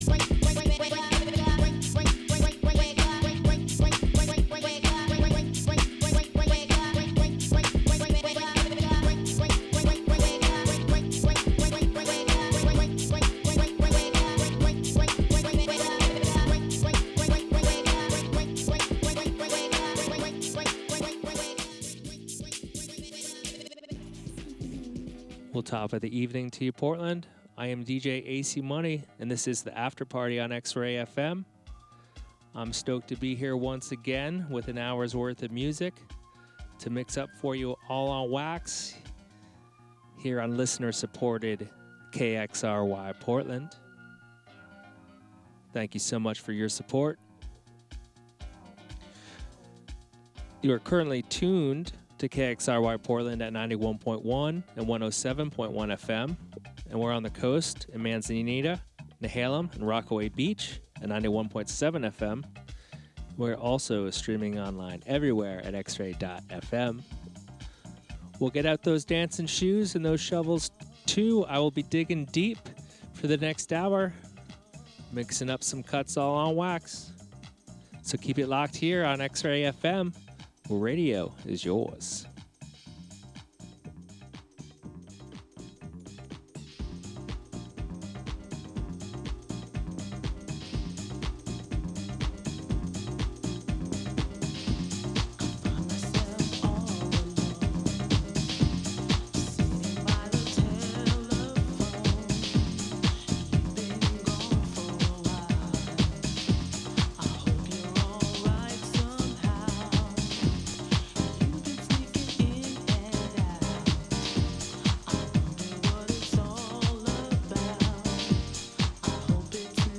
Get down to the sounds of the house underground